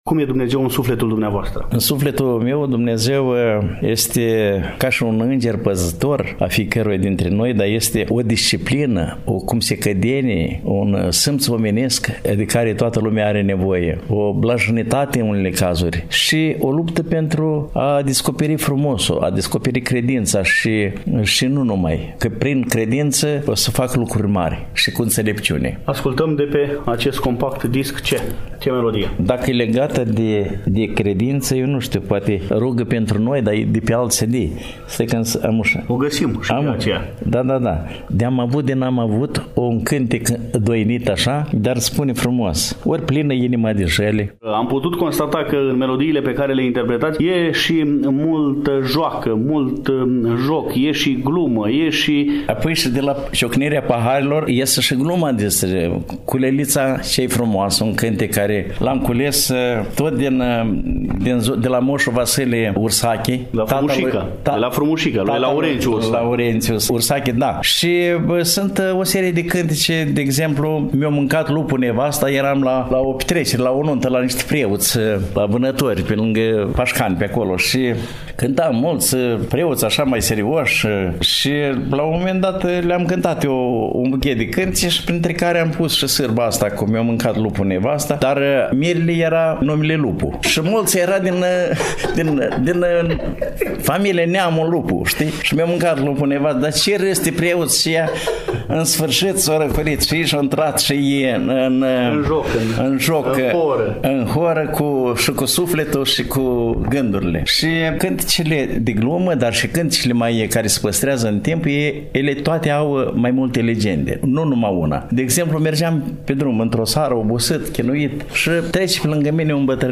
L-am întâlnit, la Chișinău, nu demult, prezenți în capitala Republicii Moldova cu prilejul etapei finale a Concursului „La izvoarele înțelepciunii” ediția 2023, ediție dedicată poetei Ana Blandiana.
4_Dialog-Interpret-de-Muzica-Populara-Mihai-Ciobanu-2-27.mp3